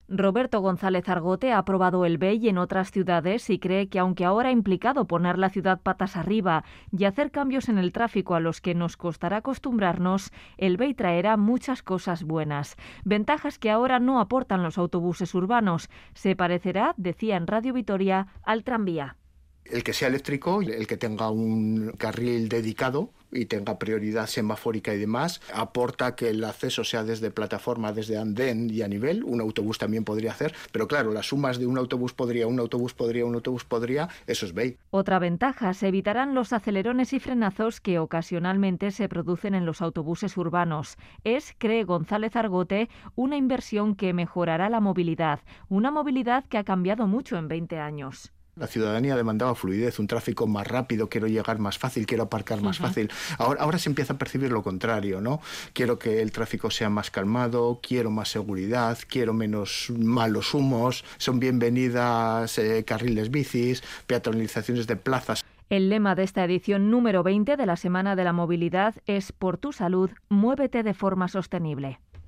Entrevistado en Radio Vitoria